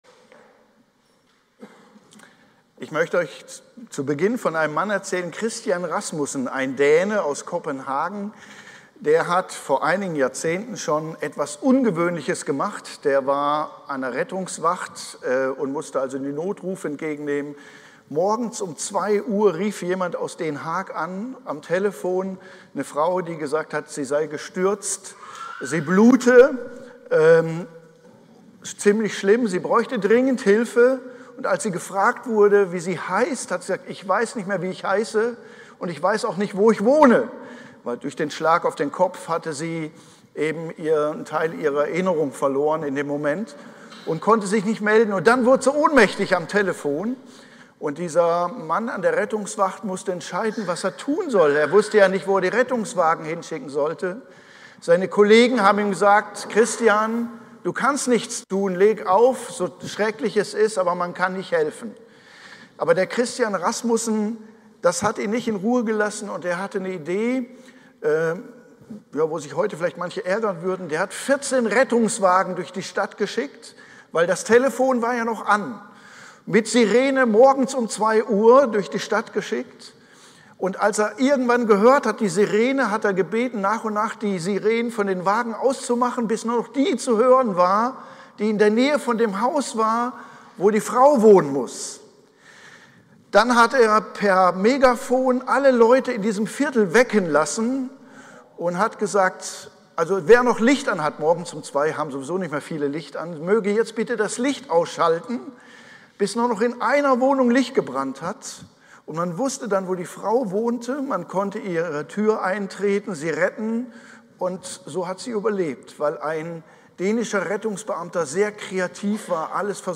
Predigt-am-03.11-online-audio-converter.com_.mp3